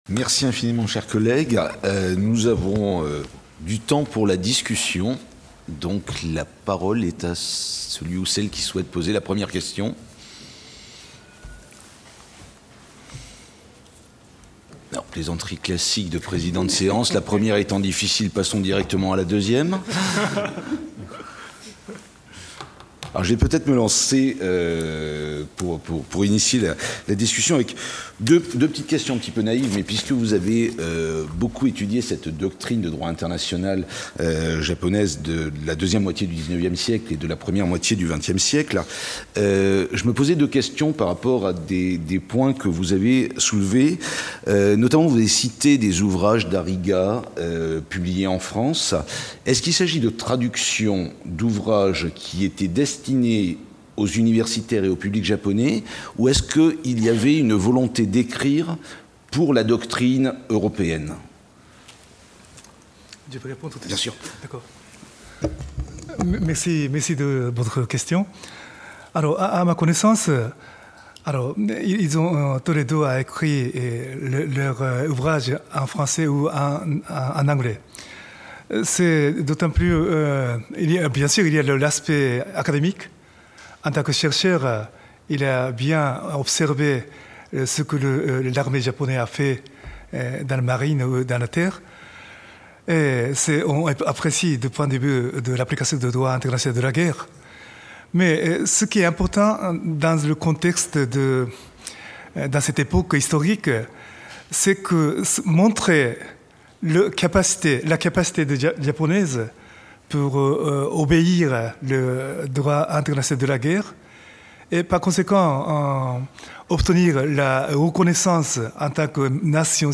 Colloque Nihon Europa, mardi 4 octobre 2016, discussion, première partie d'après-midi | Canal U